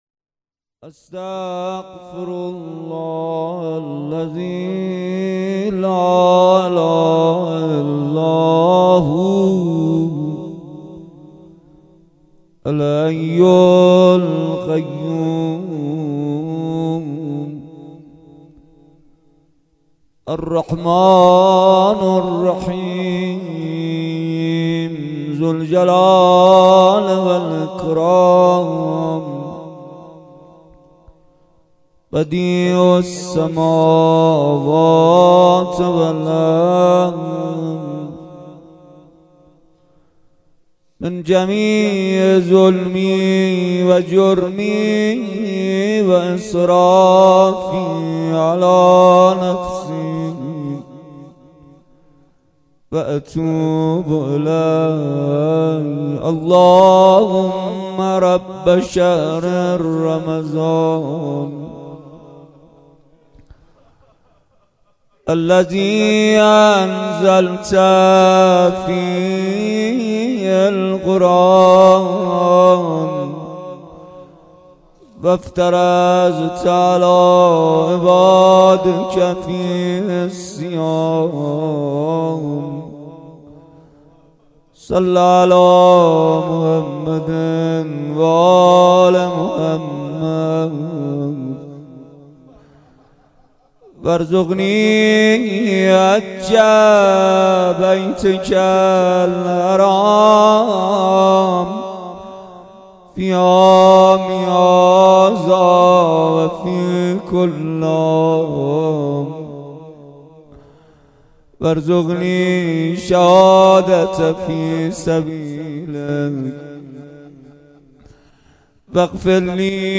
مناجات
روضه